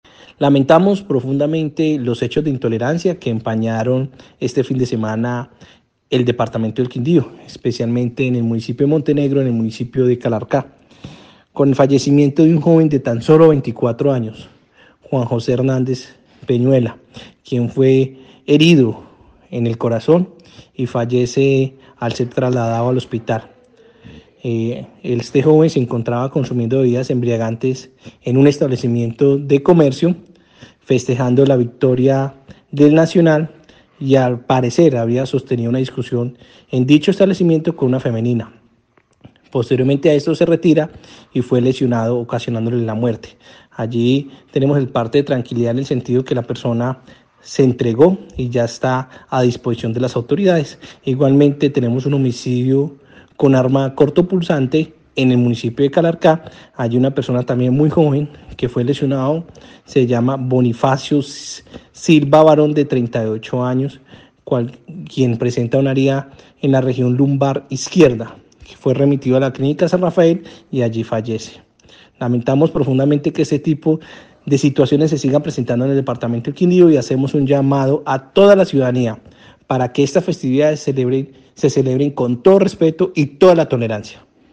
Jaime Andrés Pérez, secretario del Interior del Quindío